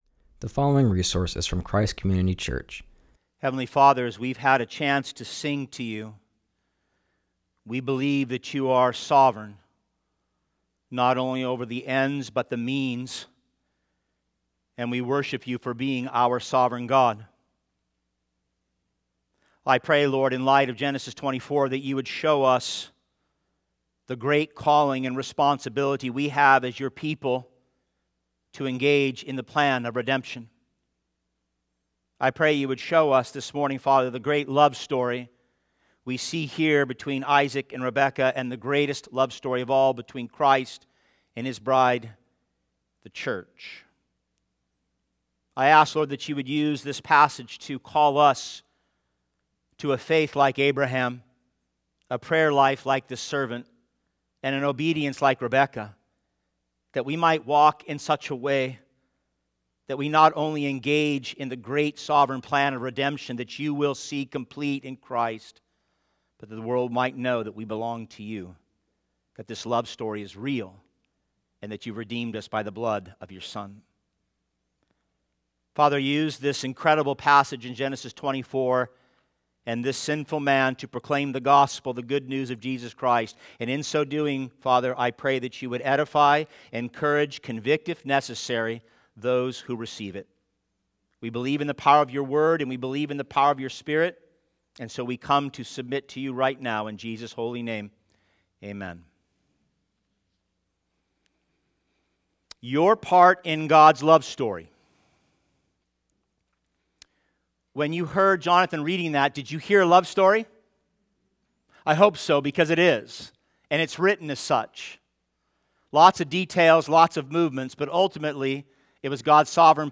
preaches from Genesis 24.